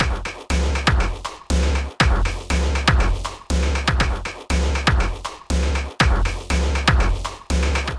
FX 74 120 Bpm
Tag: 120 bpm Weird Loops Fx Loops 1.35 MB wav Key : Unknown